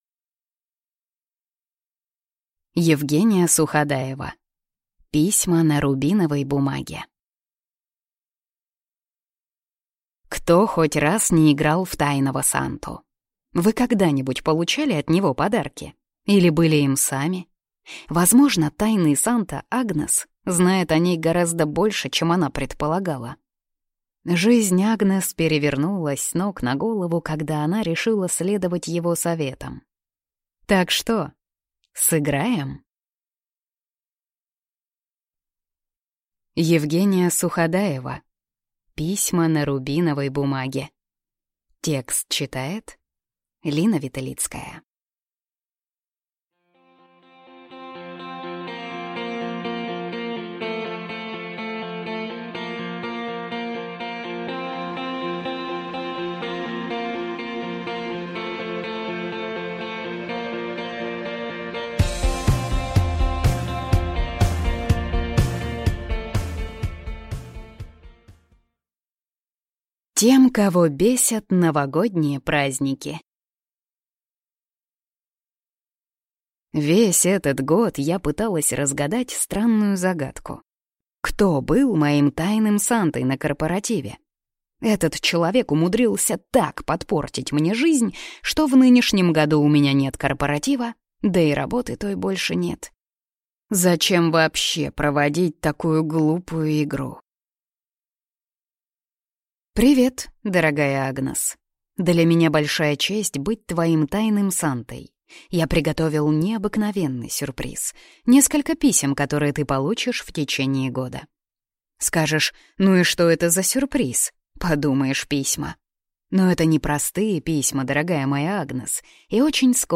Аудиокнига Письма на рубиновой бумаге | Библиотека аудиокниг